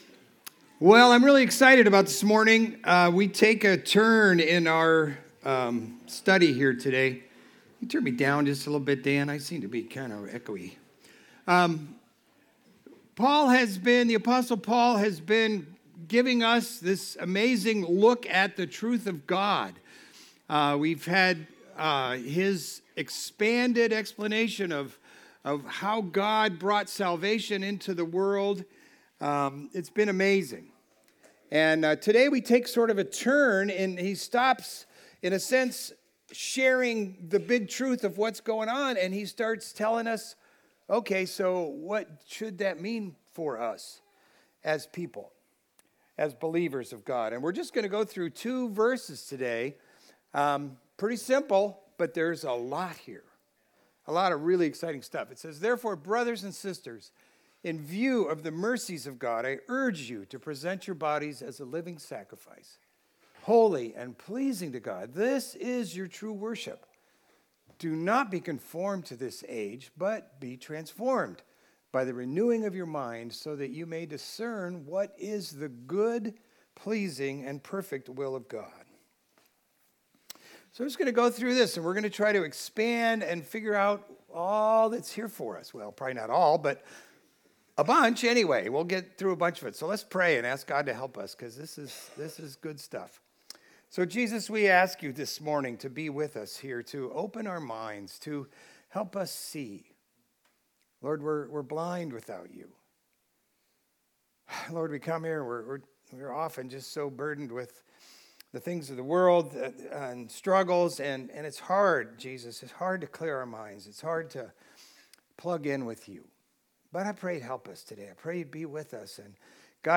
Video Audio Download Audio Home Resources Sermons Don’t Conform - Transform!